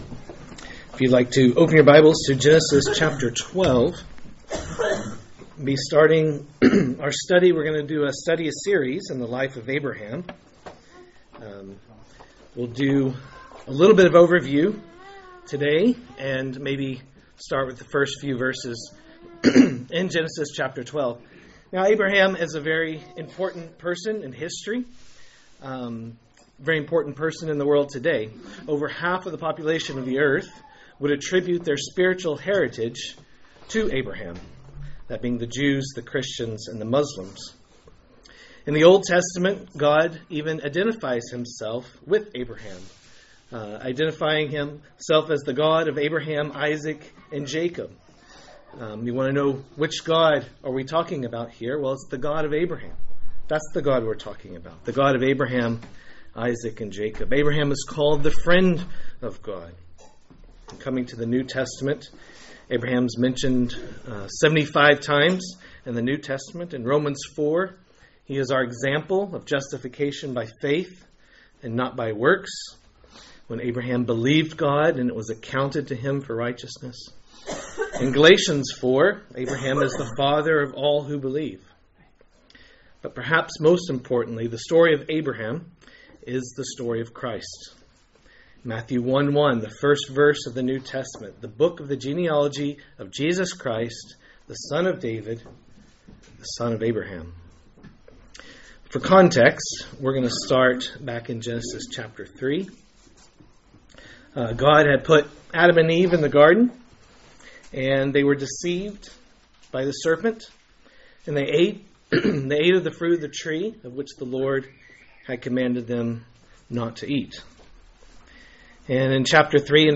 A message from the series "Abraham."